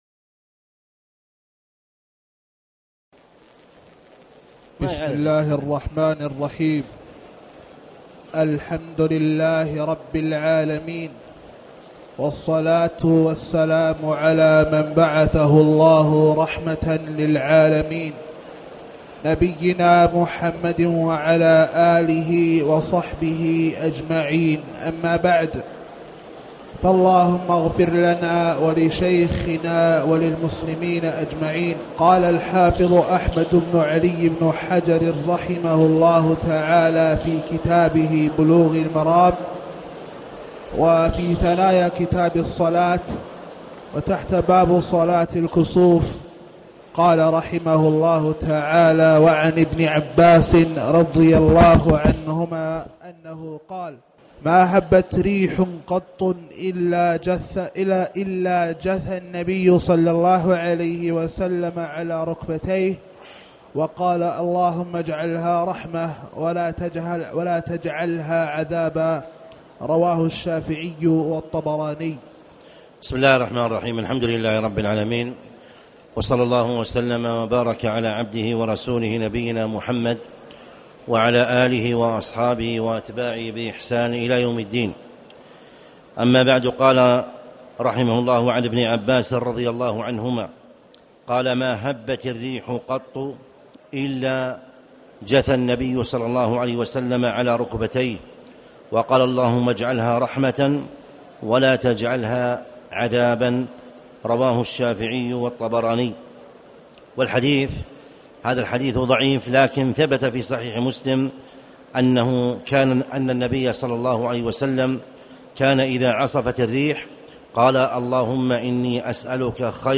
تاريخ النشر ١٦ ربيع الثاني ١٤٣٩ هـ المكان: المسجد الحرام الشيخ